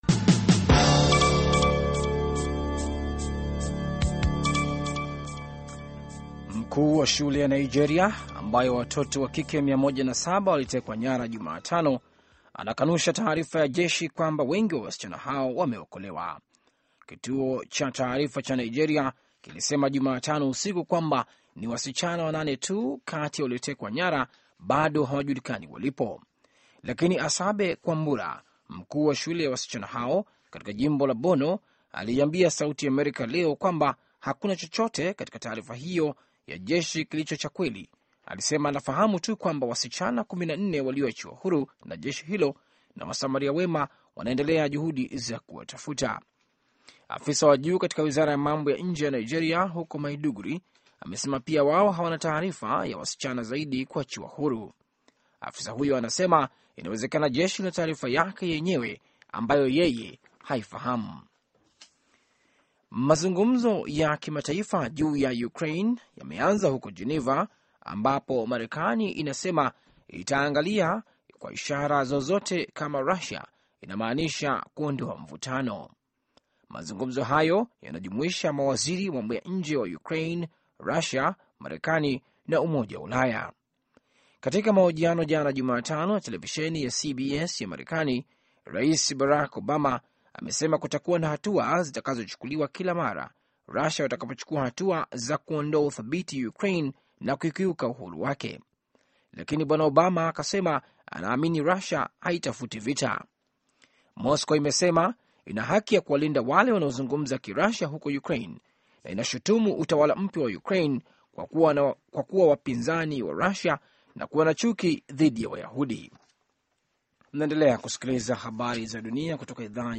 Taarifa ya Habari VOA Swahili - 6:43